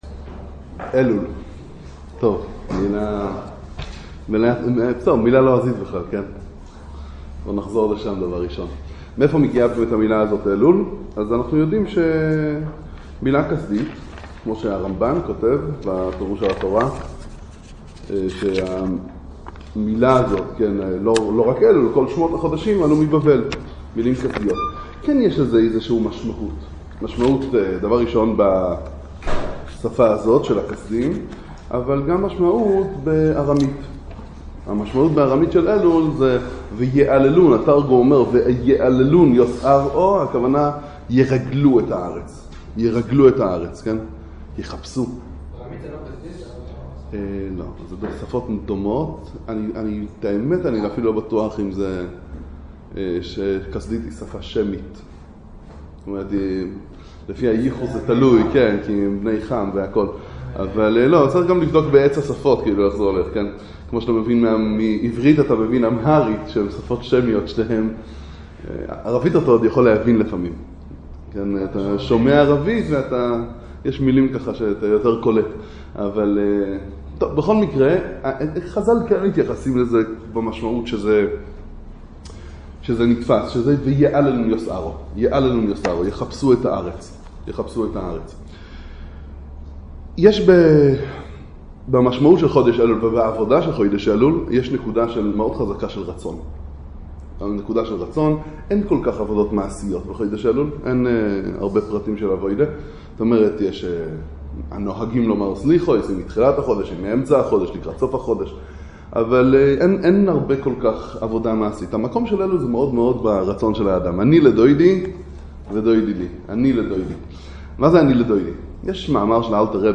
להיות יוזם ולא מגיב - עבודת חודש אלול והתעוררות הרצון - הרצאה ששית בכולל לערנען לעצמאיים רמת בית שמש